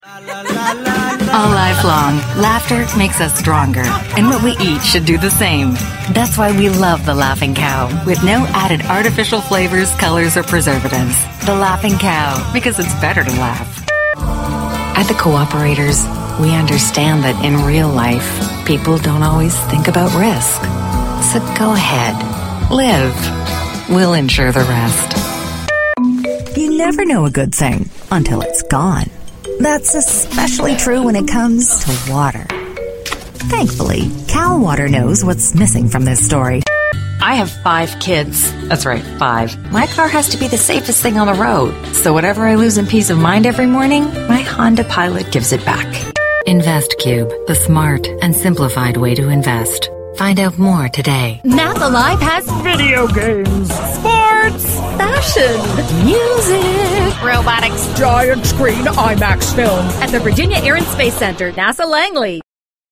İngilizce seslendirme hizmeti
Reklam Demo